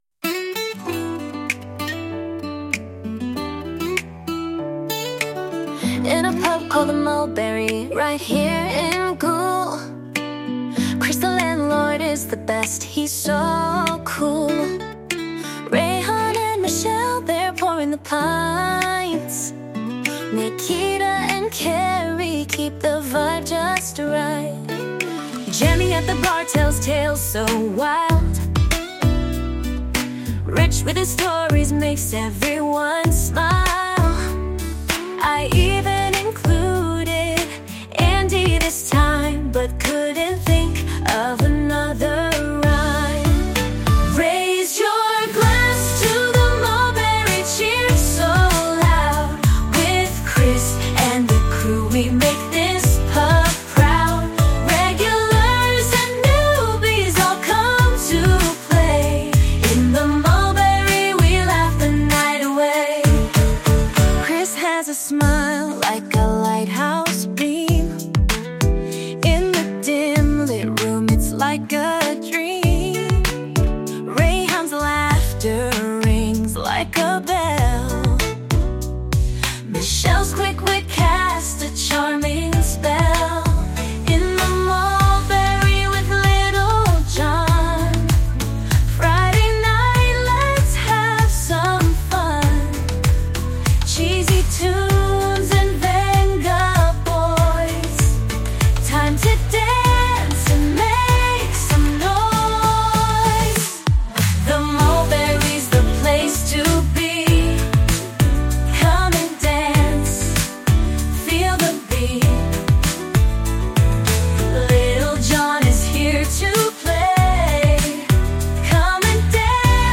I'm really impressed with how realistic they sound.
I reworked it in a girl group, R&B type sound.